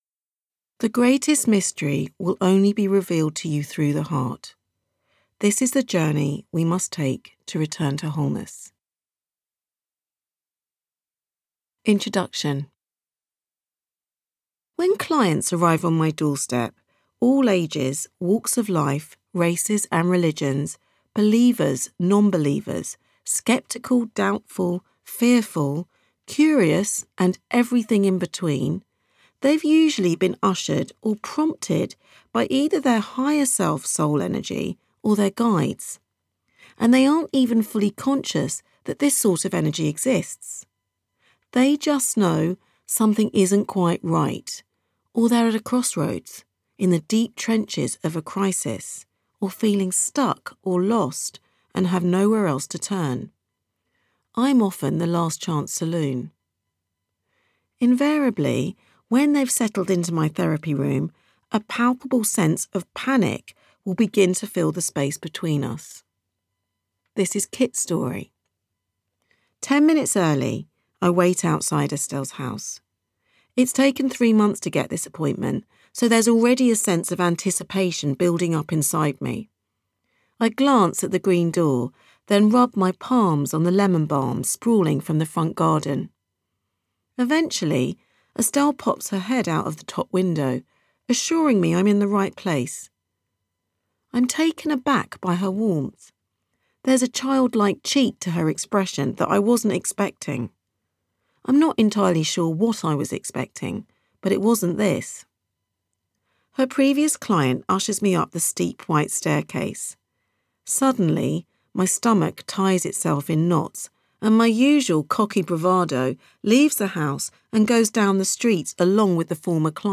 audiobook_cover